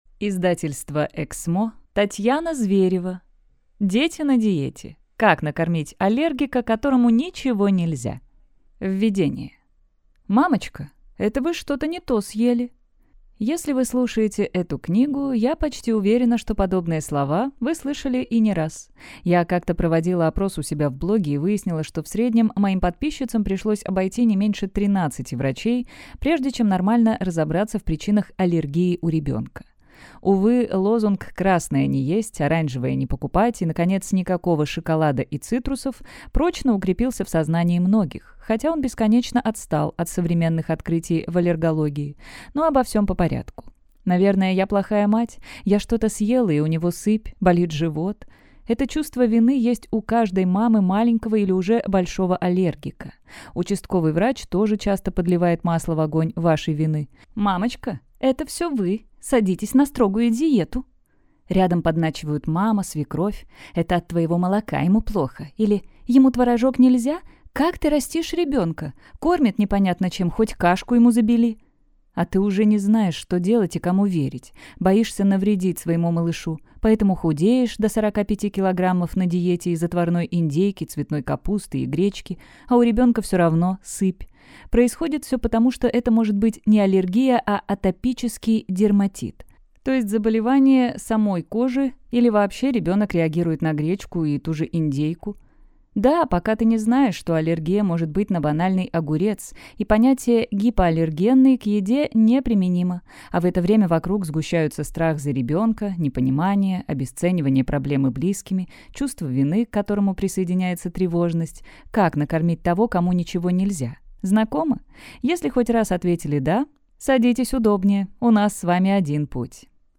Аудиокнига Дети на диете. Как накормить аллергика, которому ничего нельзя | Библиотека аудиокниг